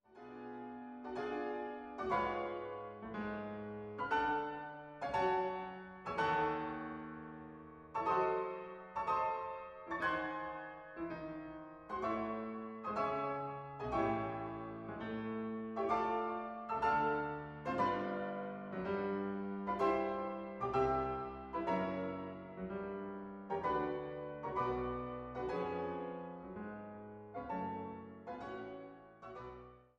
for four hands